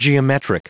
Prononciation du mot geometric en anglais (fichier audio)
Prononciation du mot : geometric